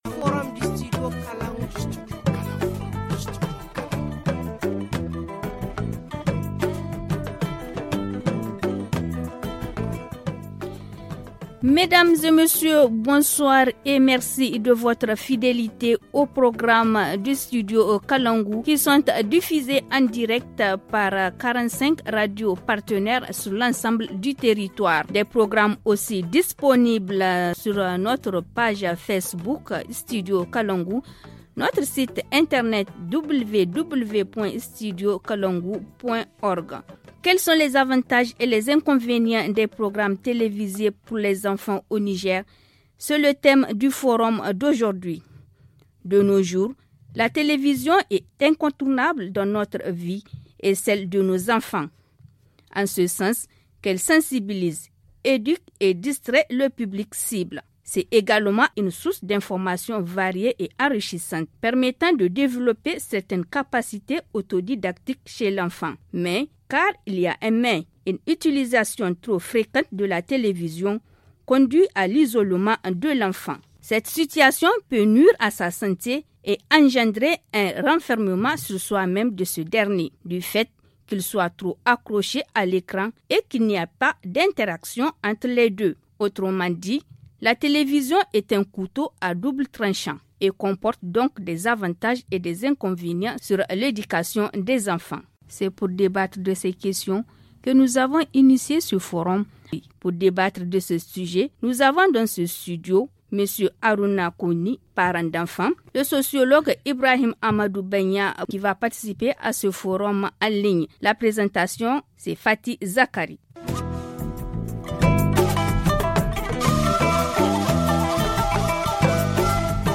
Il est au téléphone avec nous.